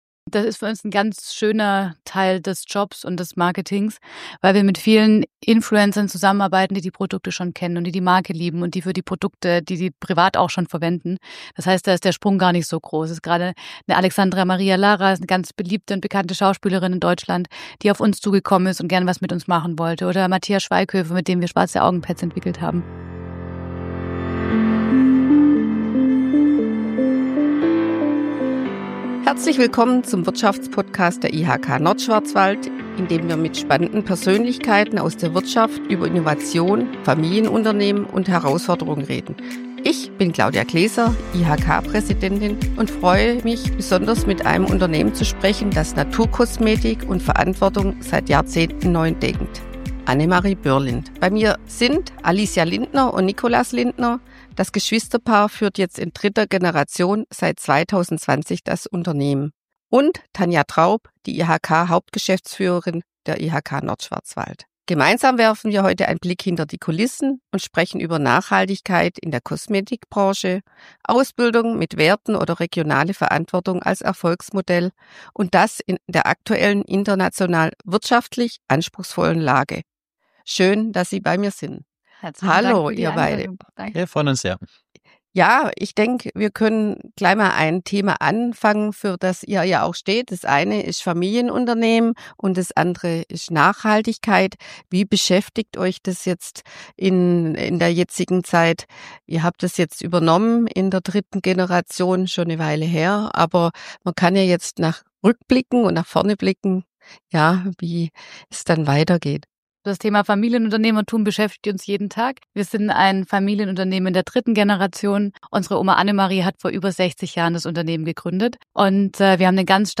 durch spannende Gespräche mit Gästen aus Wirtschaft, Politik und Gesellschaft